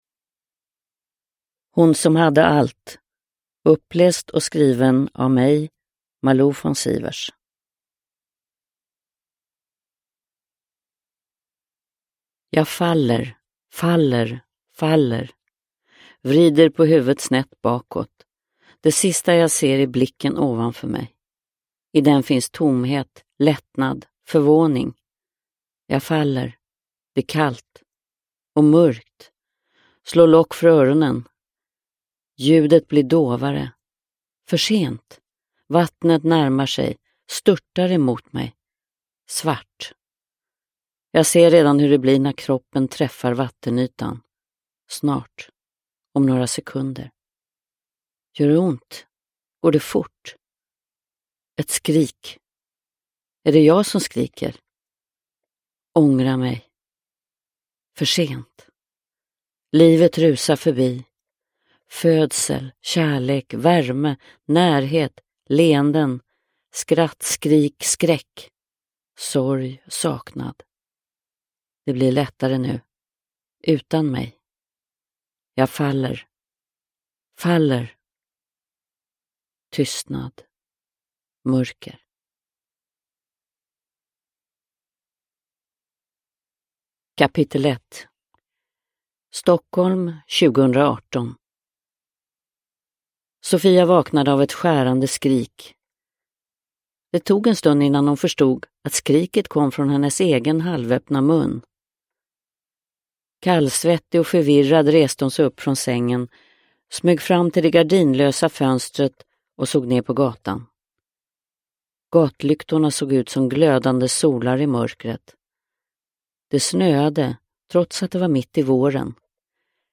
Hon som hade allt – Ljudbok – Laddas ner
Uppläsare: Malou von Sivers